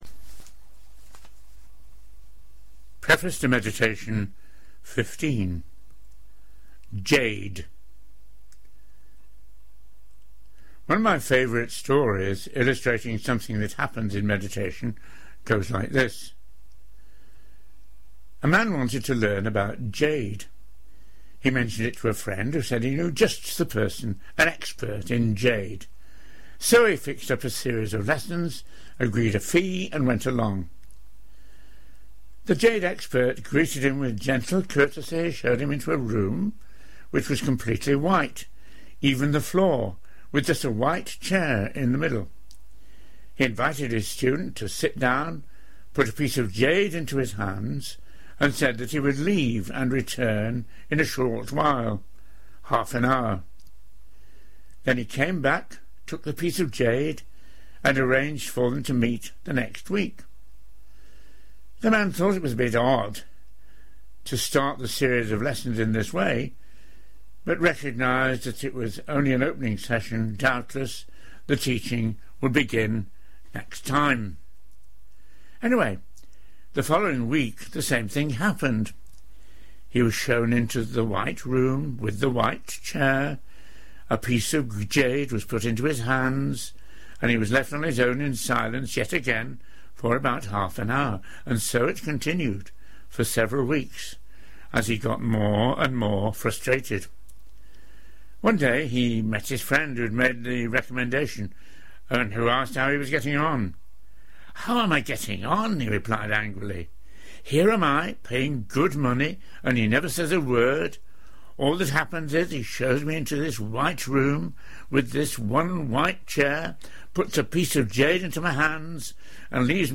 Recorded Talks